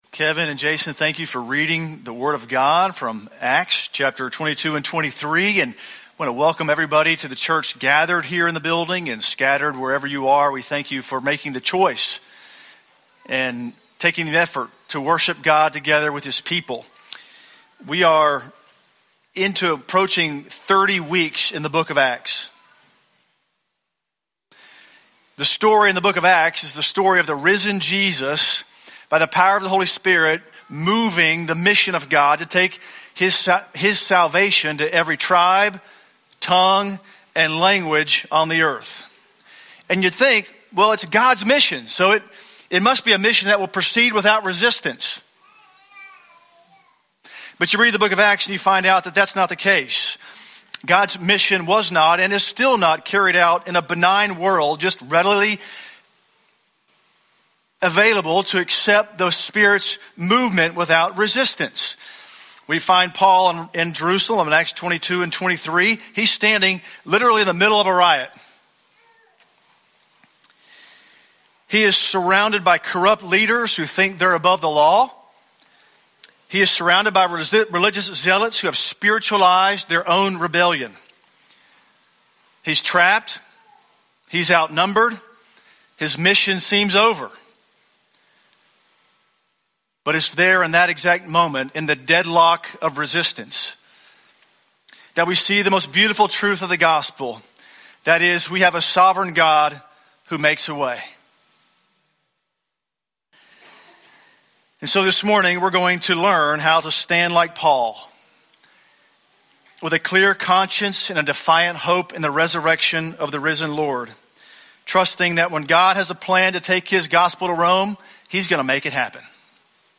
Sermon Archives
From Series: "English Sermons - 10:15"